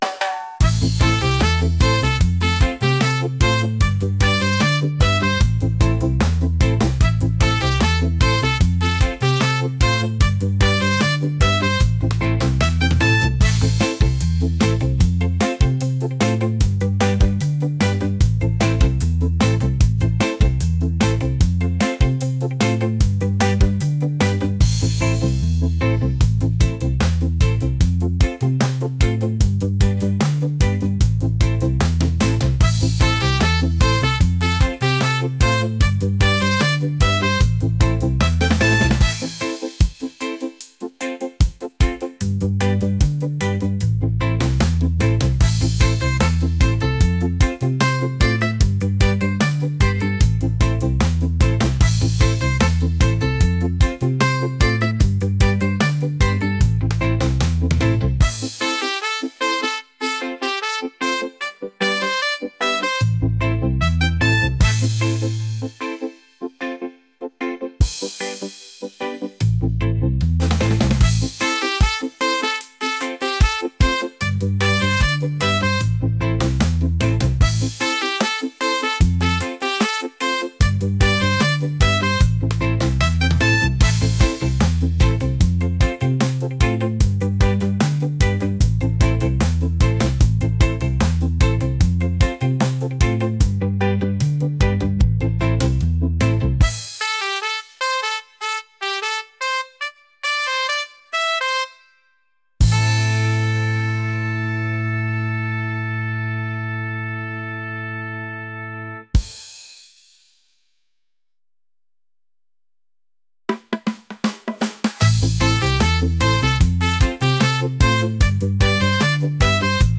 positive | reggae | upbeat